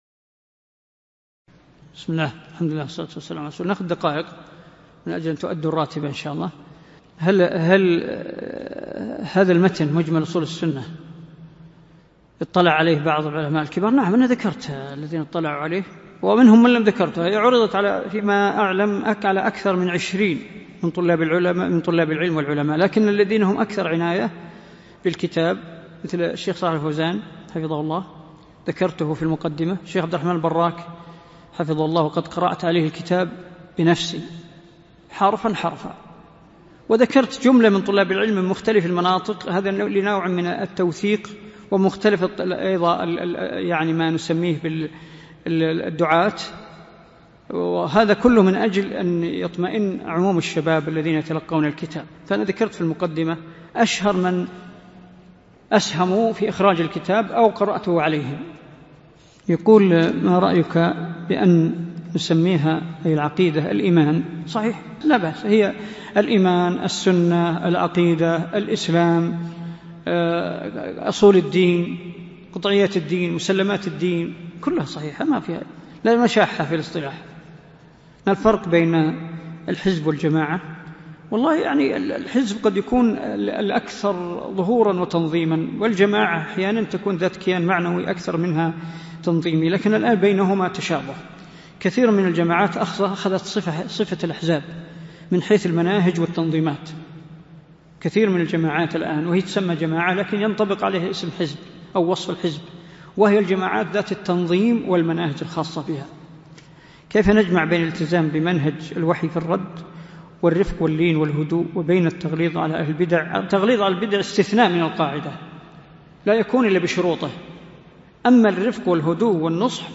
عنوان المادة الدرس ( 2) شرح كتاب مجمل أصول أهل السنة والجماعة في العقيدة تاريخ التحميل الثلاثاء 20 ديسمبر 2022 مـ حجم المادة 22.78 ميجا بايت عدد الزيارات 198 زيارة عدد مرات الحفظ 61 مرة إستماع المادة حفظ المادة اضف تعليقك أرسل لصديق